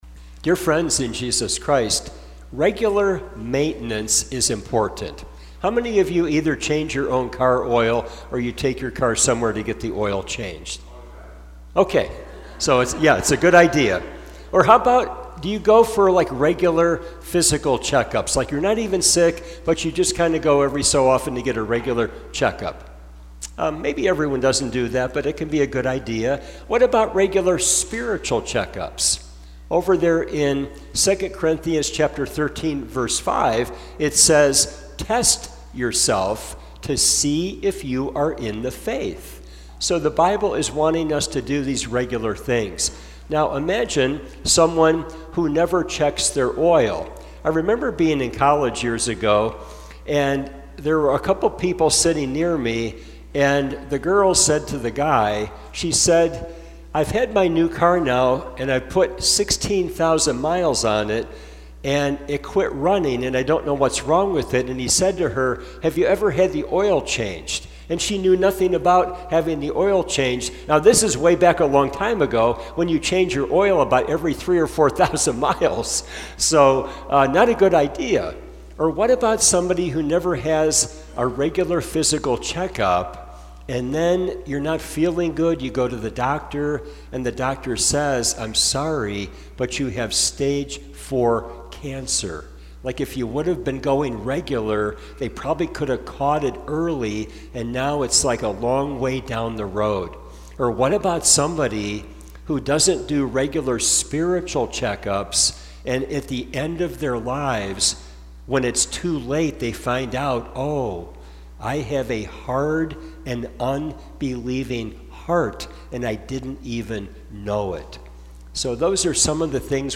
Is your heart hard and unbelieving? Please benefit from this sermon - before it is too late!